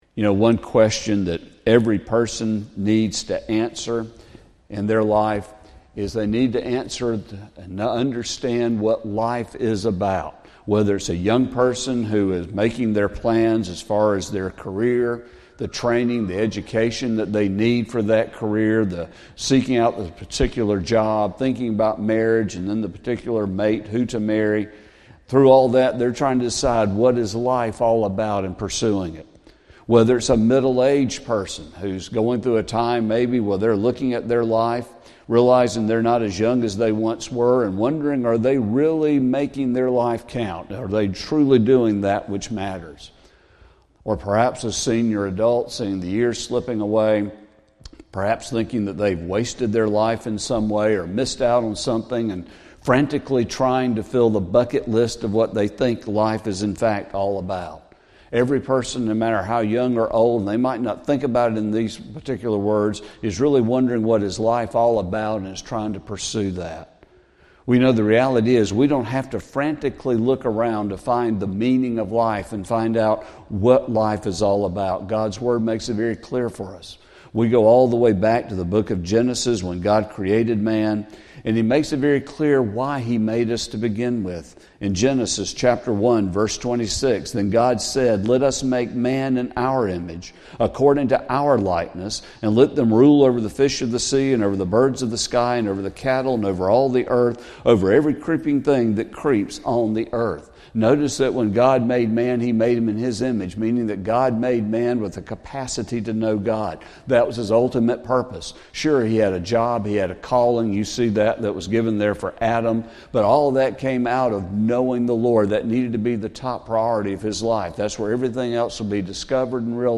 Sermon | February 16, 2025